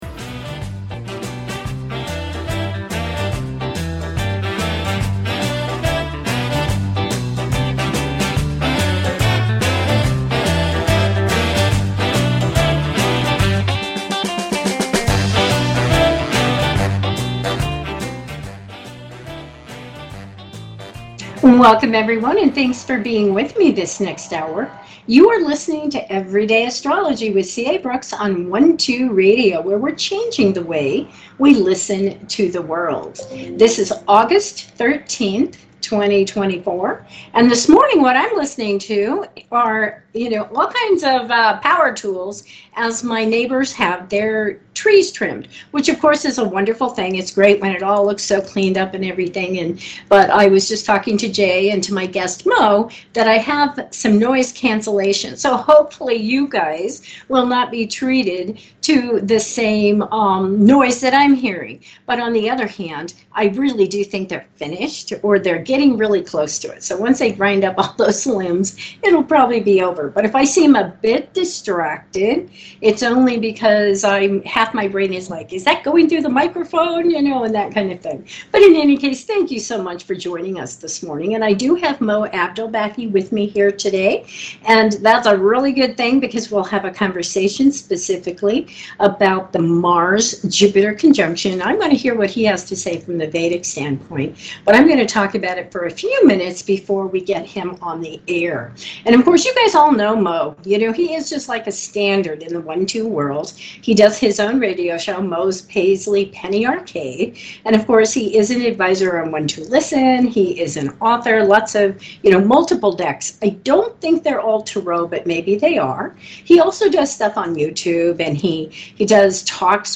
Genre: Astrology